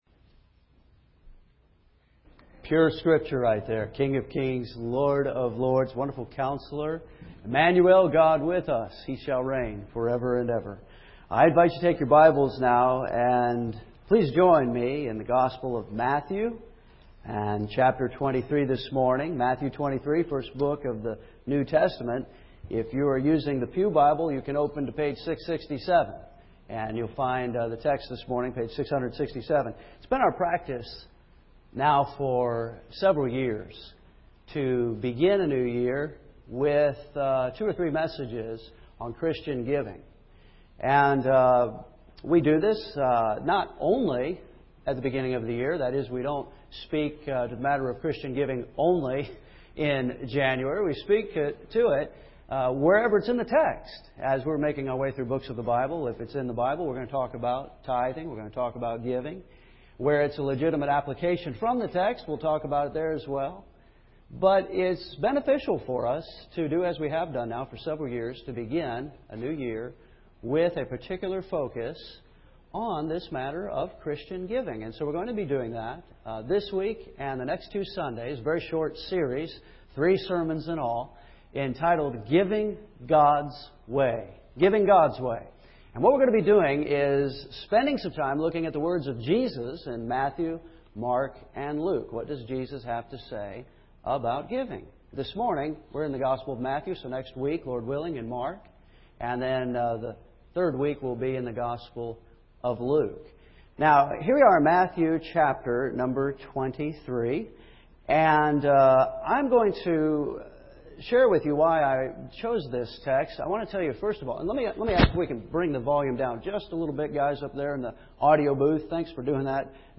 And so we will have a very short series—just three sermons—a series entitled, “God on Giving.” And we’re going to be looking at what Jesus says about giving in the Gospels Matthew, Mark, and Luke.